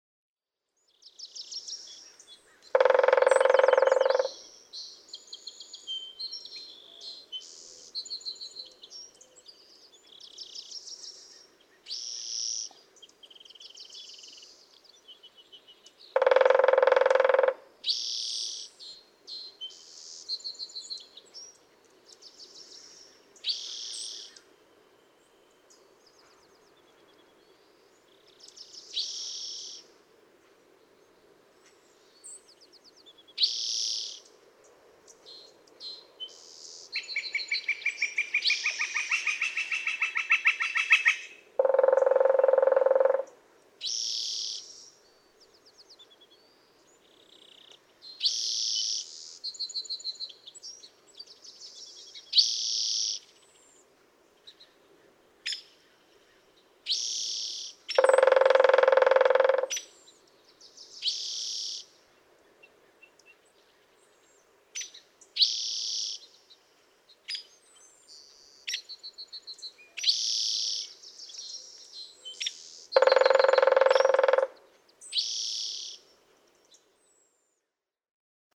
Northern flicker
♫69. A drumming and singing (the wick-wick-wick song, at 0:36) flicker, accompanied by a typical Pacific Coast spotted towhee (e.g., 0:12).
Montaña de Oro State Park, Los Osos, California.
069_Northern_Flicker.mp3